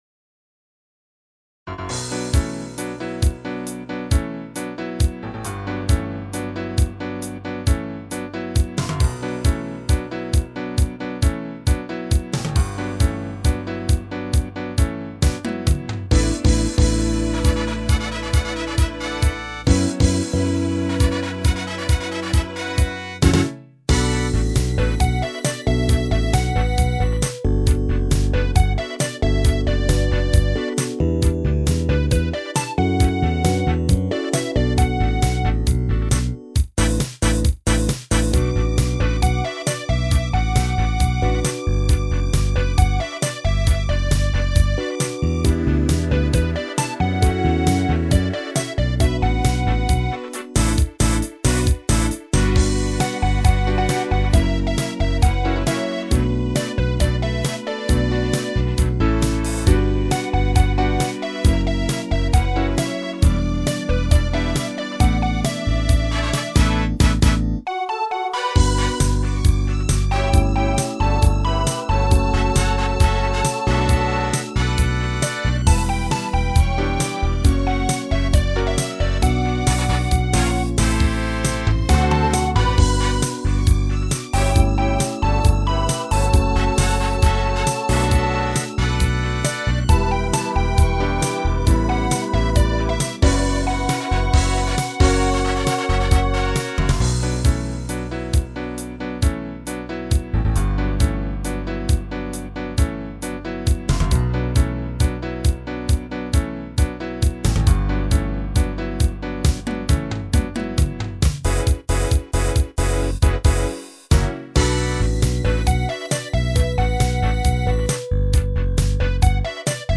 各パートを左右センターに振っているところも類似。